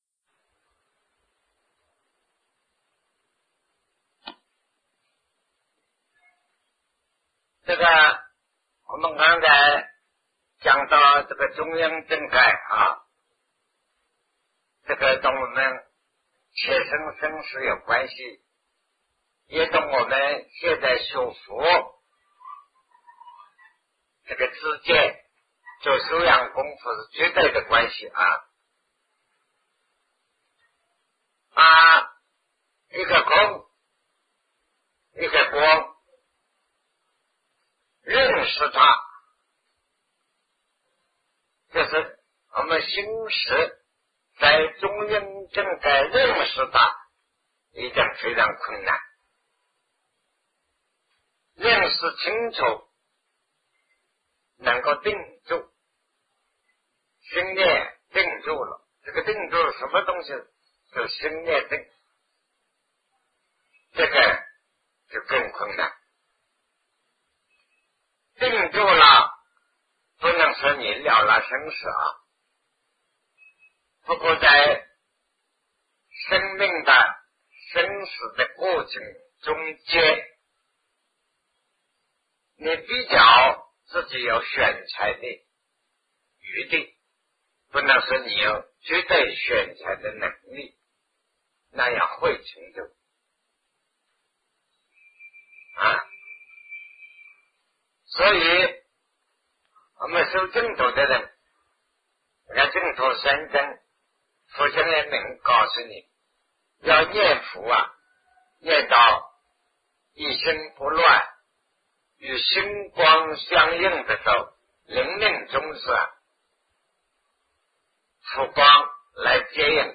禅定修行与生死的关系 南师讲唯识与中观（1980代初于台湾014(上)